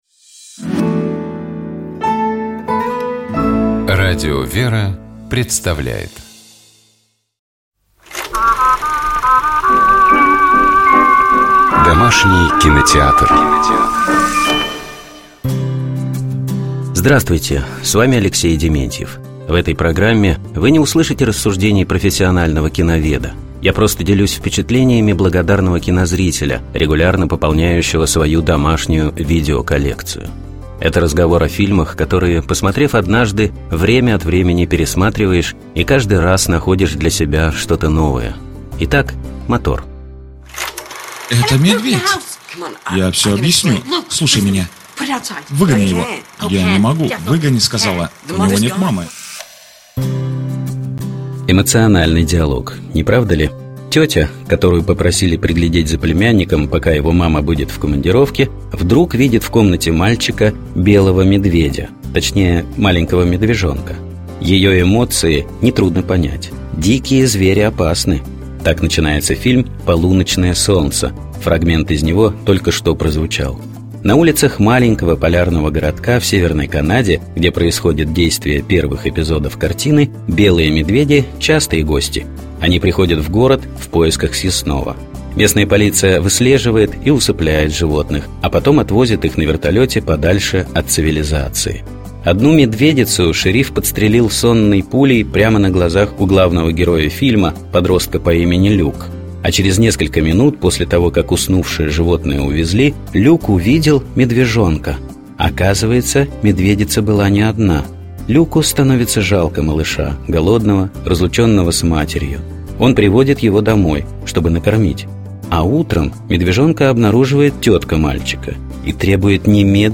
Так начинается кинофильм «Полуночное солнце» — фрагмент из него только что прозвучал.